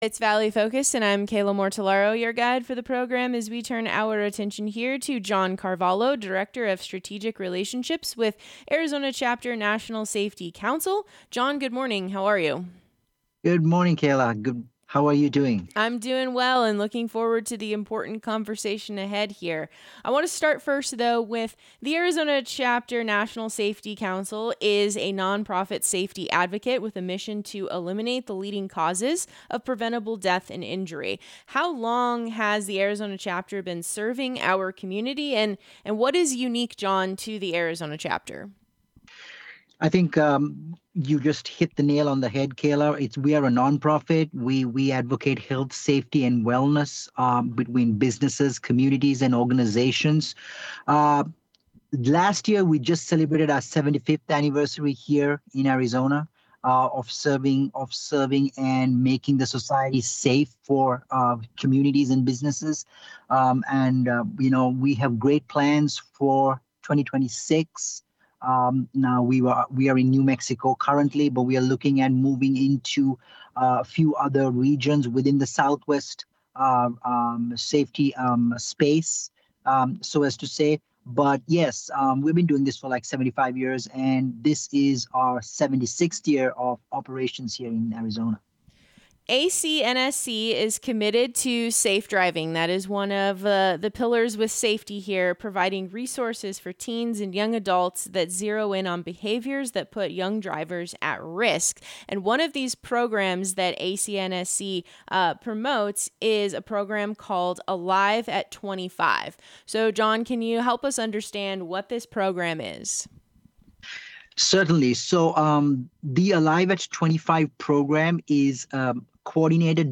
The program airs on KDUS AM 1060.